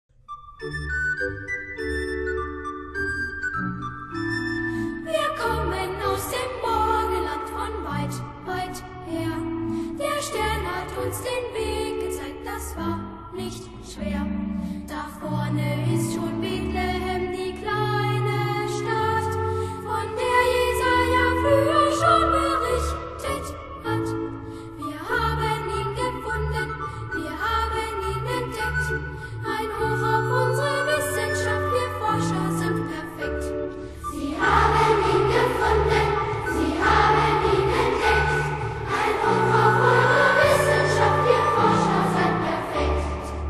12 Rollen und Chor
Instrumente: Flöte, Klavier